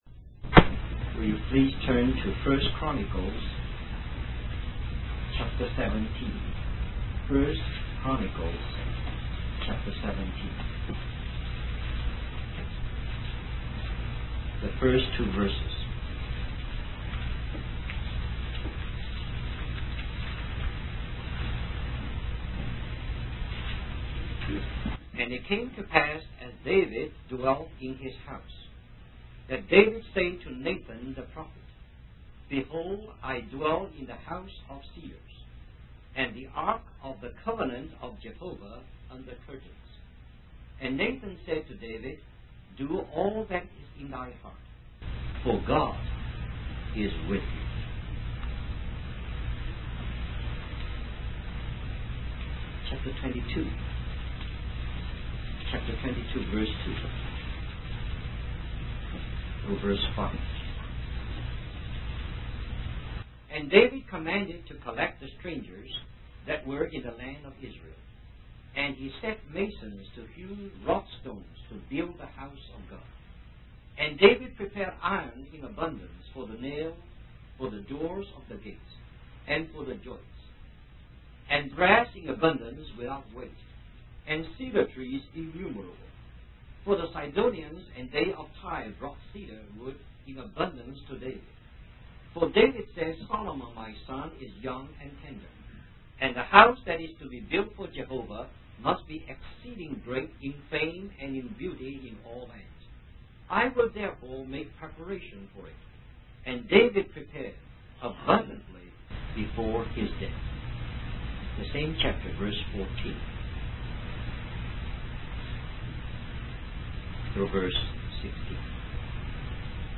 In this sermon, the preacher discusses the story of a sister who came to the Lord and poured a pound of pure love upon him.